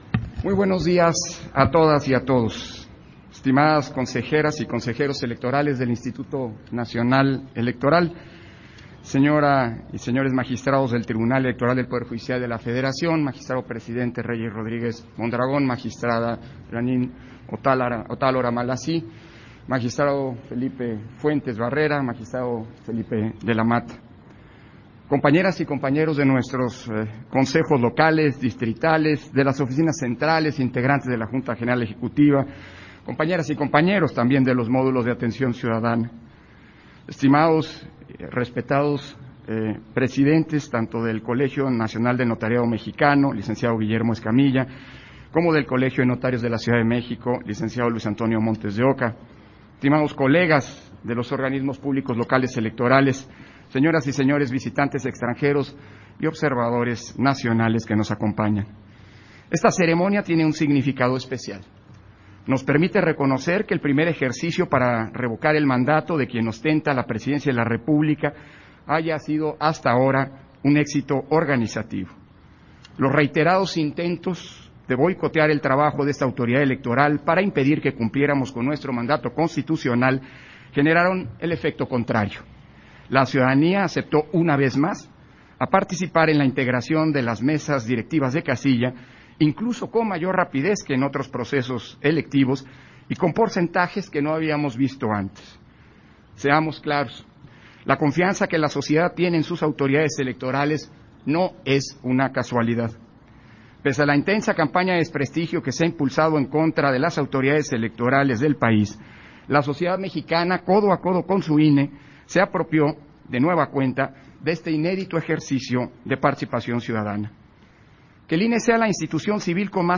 Intervención de Lorenzo Córdova, en la ceremonia de Honores a la Bandera, en el proceso de Revocación de Mandato 2022